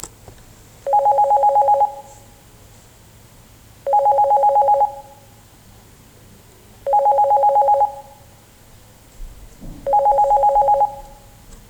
【NAKAYO（ナカヨ）ST101A 着信音サンプル】
■着信音　J